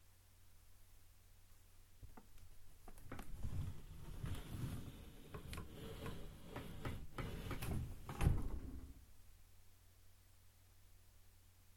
Slow/Close end drawer
Environment - Bedroom, absorption eg, bed, curtains, carpet. Duration - 11s Description - Wooden, drawer, closing, slowly drags, shuts